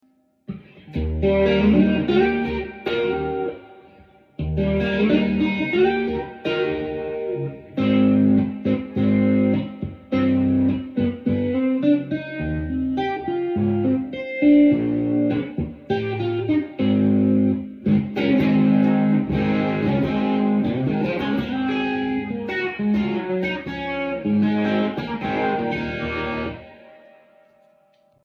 jazzmaster tone for my upcoming sound effects free download